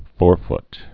(fôrft)